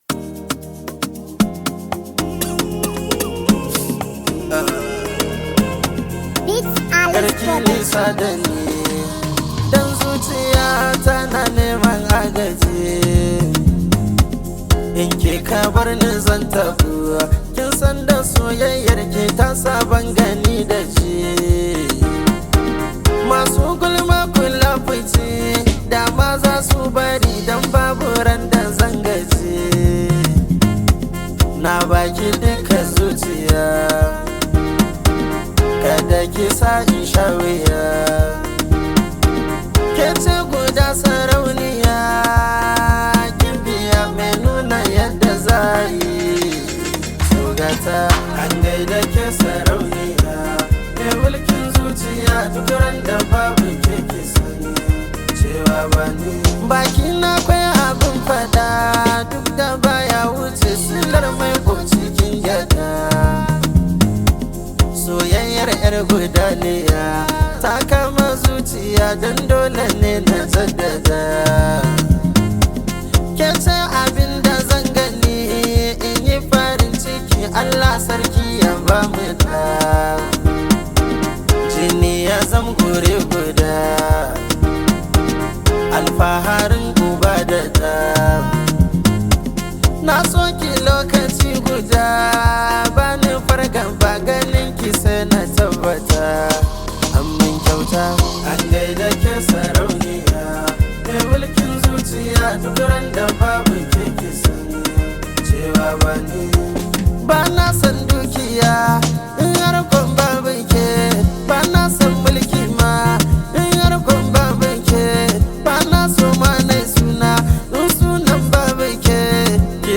blends Afrobeat with contemporary sounds
With its upbeat tempo and catchy sounds